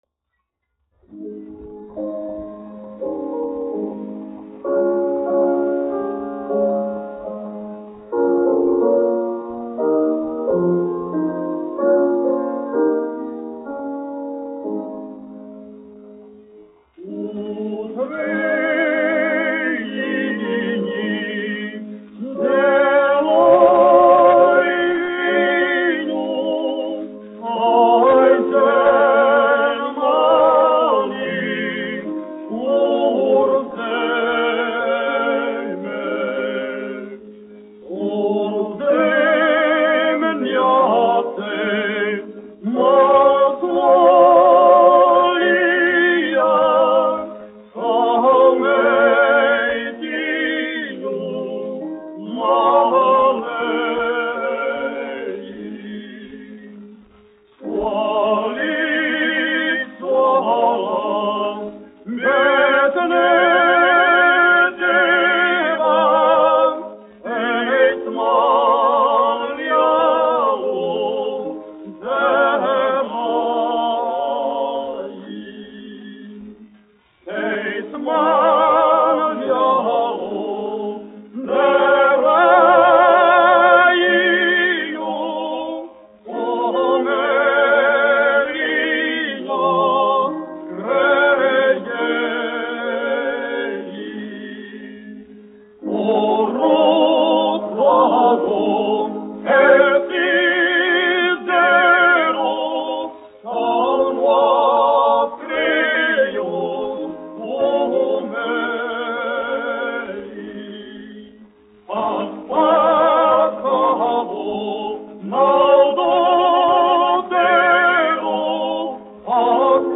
1 skpl. : analogs, 78 apgr/min, mono ; 25 cm
Latviešu tautasdziesmas
Vokālie dueti
Skaņuplate